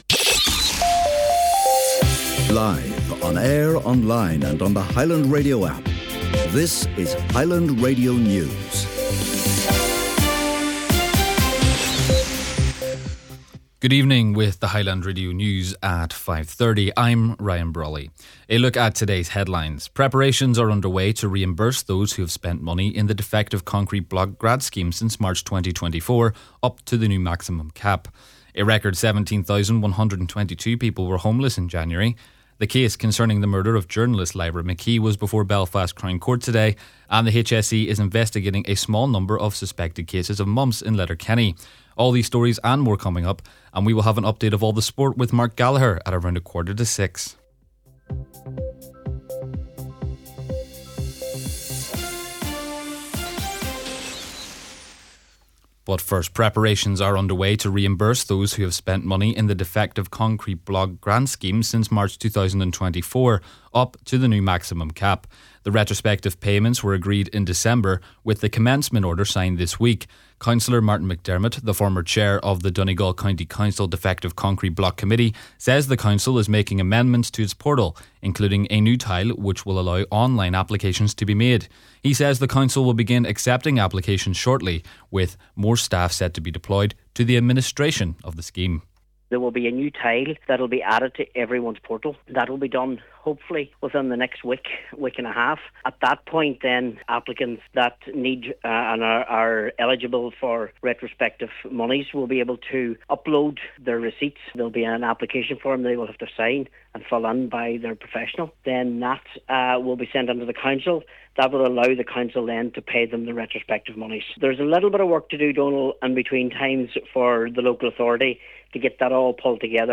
Main Evening News, Sport, and Nuacht – Friday, February 27th